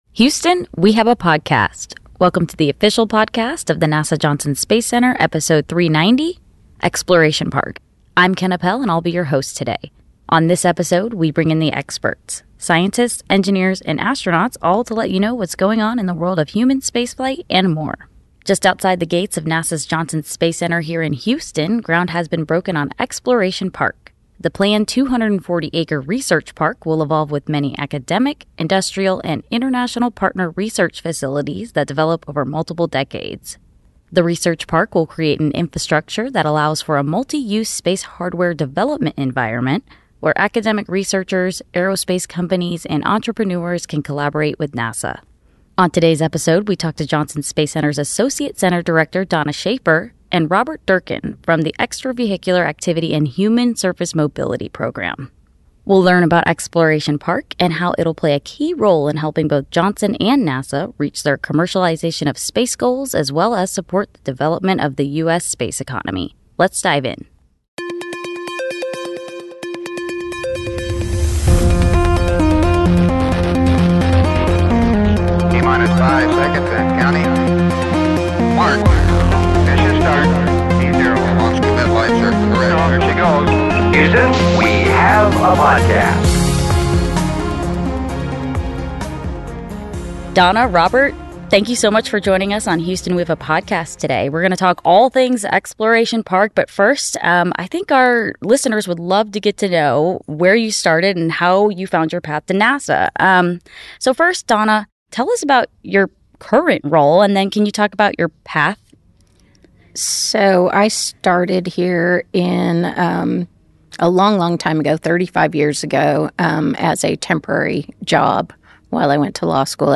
Two NASA experts talk about Exploration Park, a research facility near Johnson Space Center will enable collaboration with the agency on space hardware development.